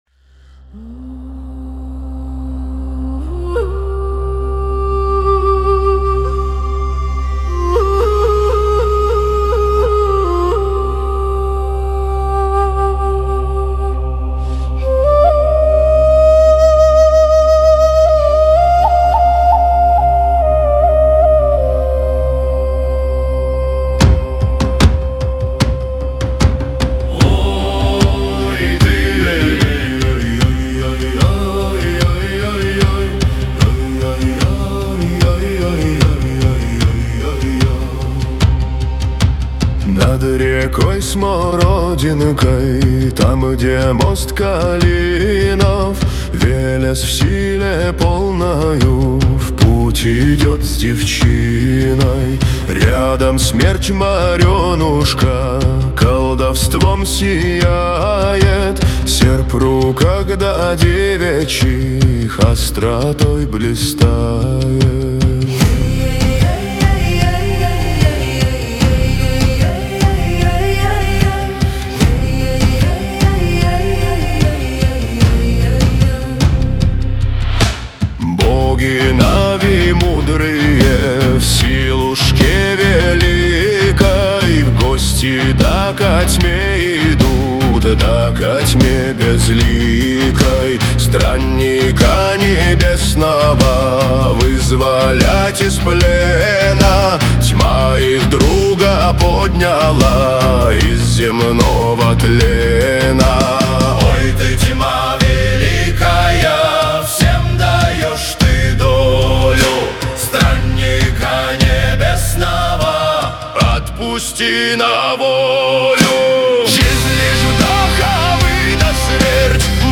Русские песни
ЛИРИЧЕСКАЯ МЕЛОДИЯ